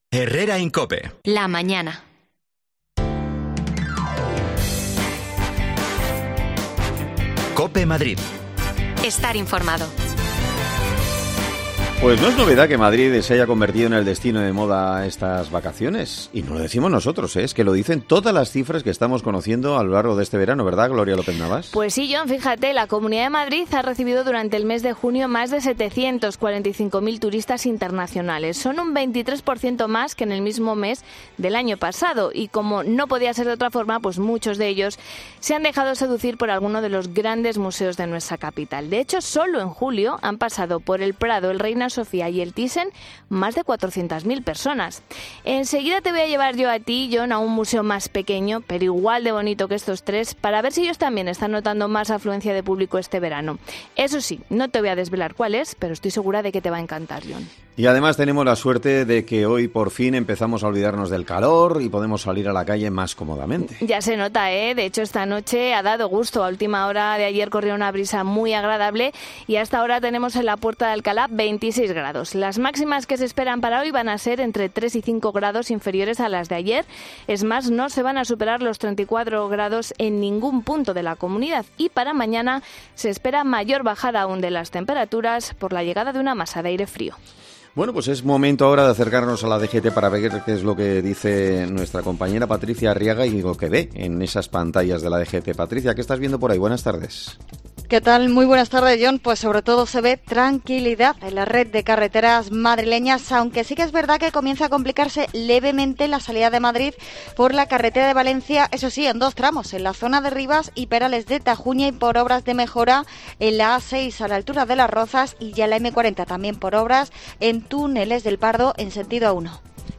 Con el aumento de turistas este verano, espacios culturales como el Museo del Romanticismo, han recibido más visitas. Hablamos con una de las trabajadoras